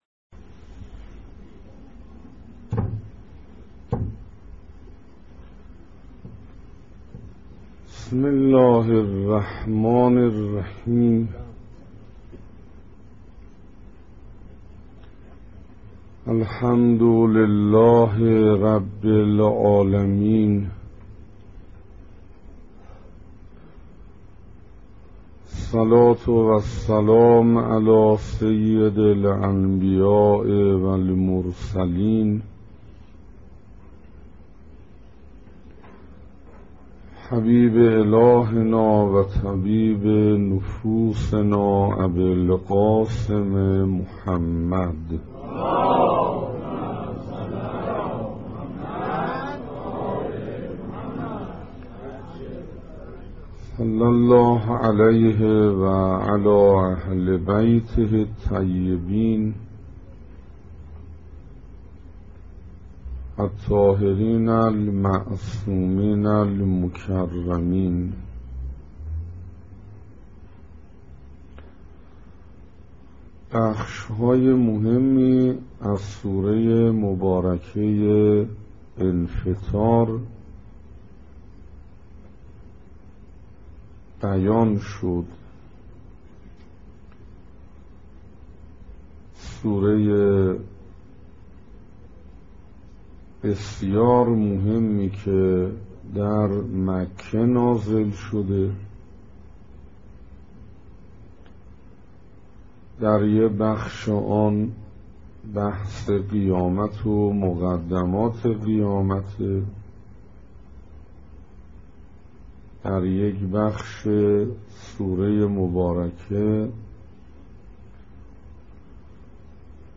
سخنراني ششم
صفحه اصلی فهرست سخنرانی ها تفسير سوره انفطار سخنراني ششم (تهران حسینیه حضرت علی اکبر (ع)) جمادی الثانی1428 ه.ق - خرداد1386 ه.ش دانلود متاسفم..